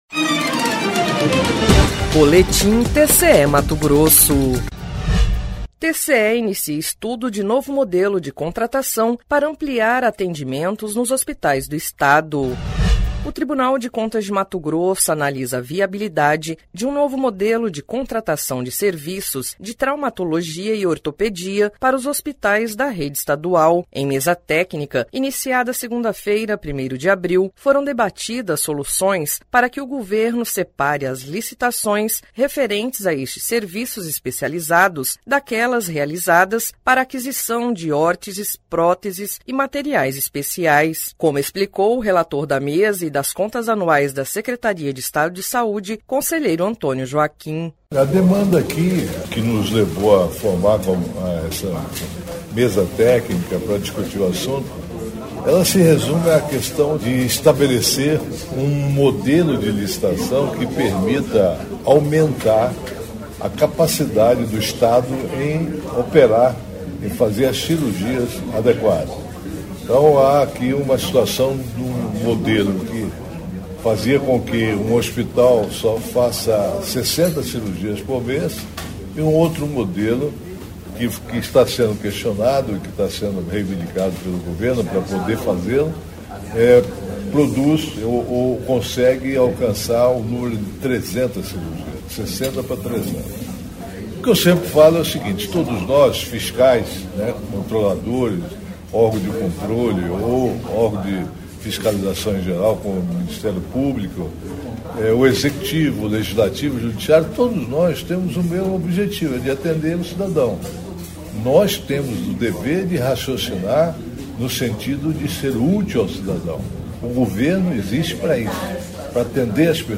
Sonora: Antonio Joaquim - relator da mesa-técnica e das contas anuais da SES/MT
Sonora: Valter Albano – conselheiro presidente da CNPJur
Sonora: Gilberto Figueiredo - secretário da SES-MT